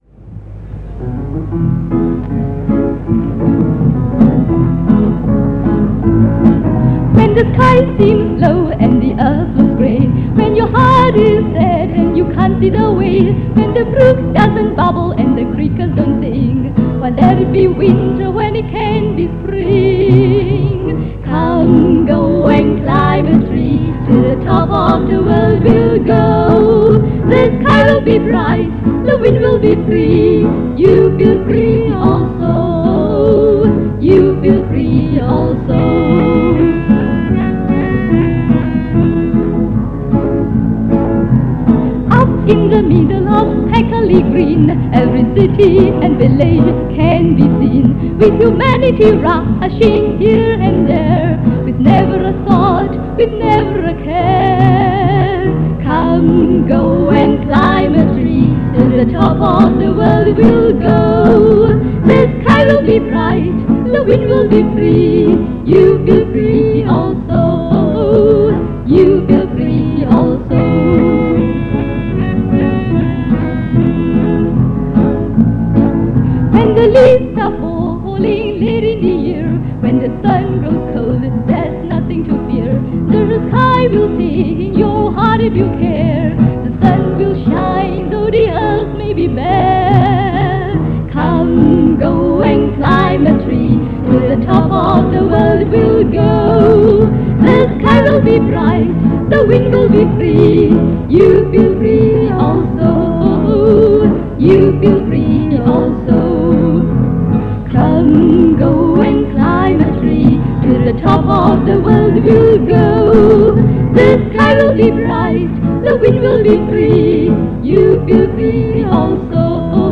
Location : Ipoh
This part features more songs from the MGS Singout of 1968.